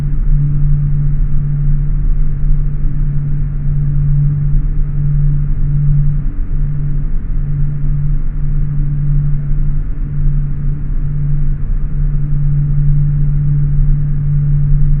cfm-hum.wav